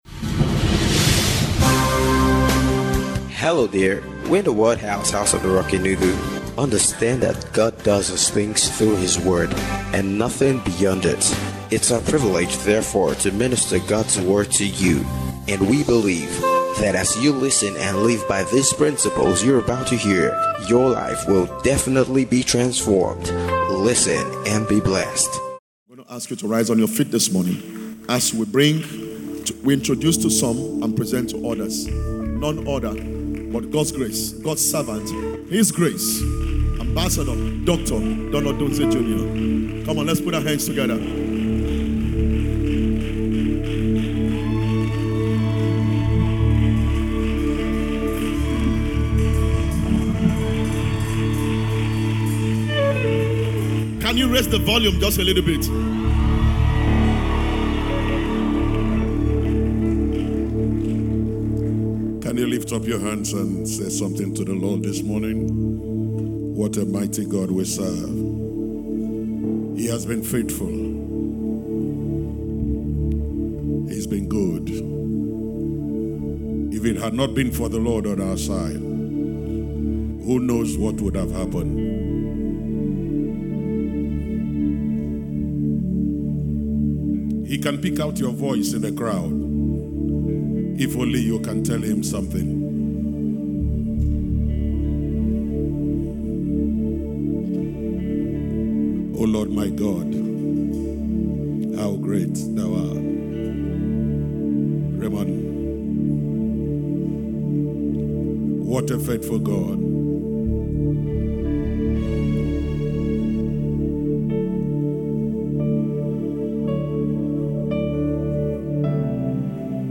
RELATIONSHIP SUMMIT 2026 - FRESH DEW SERVICE